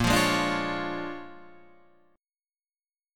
Bb7#9 chord